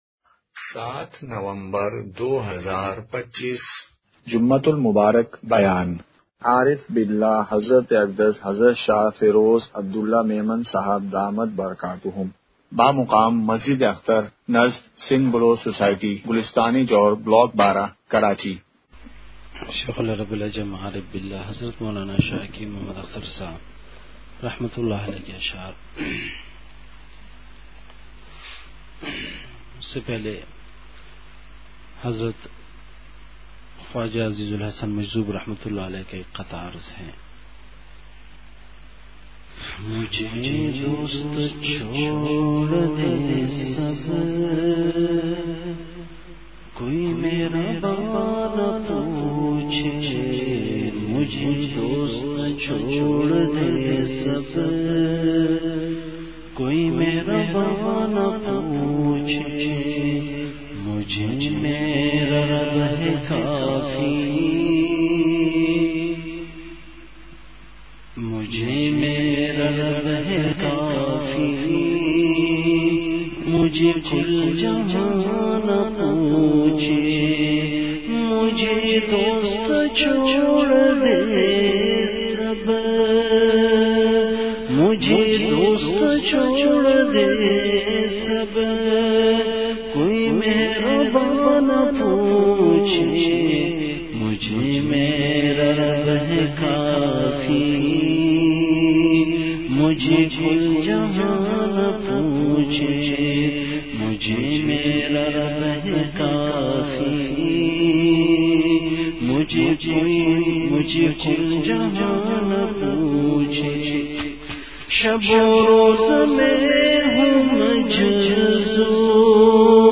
جمعہ بیان ۷ نومبر ۲۵ء:بے حیائی اور اس کا وبال !
مقام:مسجد اختر نزد سندھ بلوچ سوسائٹی گلستانِ جوہر کراچی